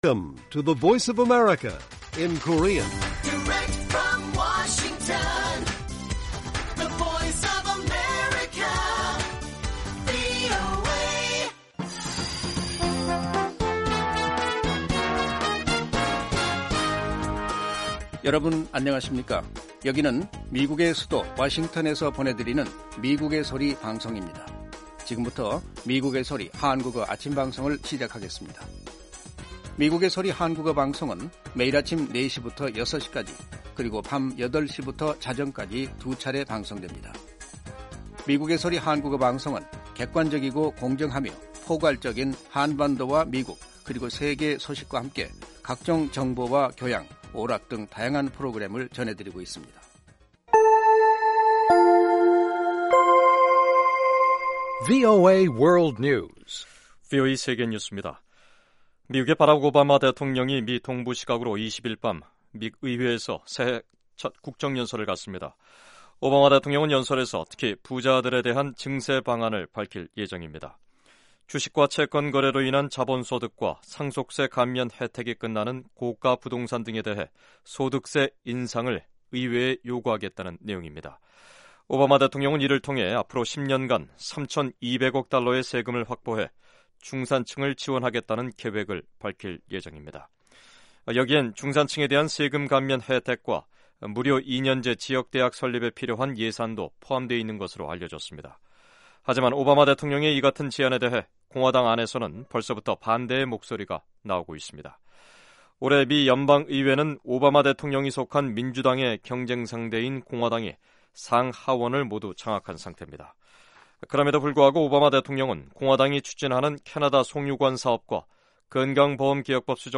VOA 한국어 방송의 아침 뉴스 프로그램 입니다. 한반도 뉴스와 함께 밤 사이 미국과 세계 곳곳에서 일어난 생생한 소식을 빠르고 정확하게 전해드립니다.